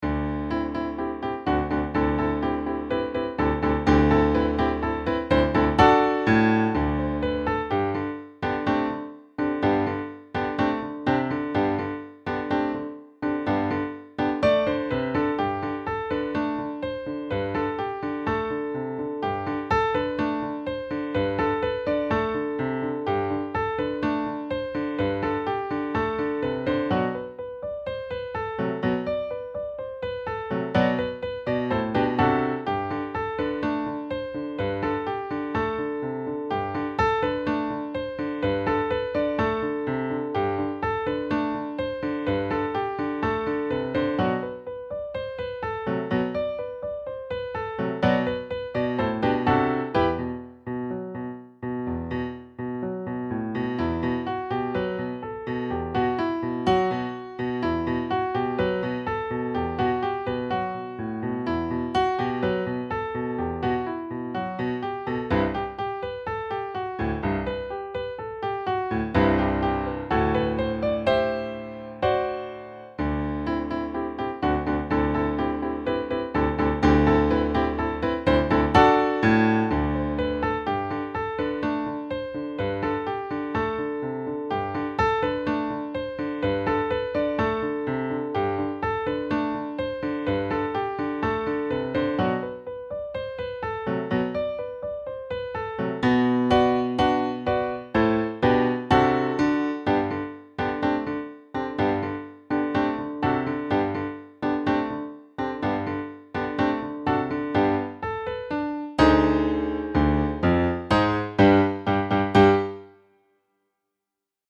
for the Intermediate Pianist
• Piano